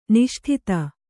♪ niṣṭhita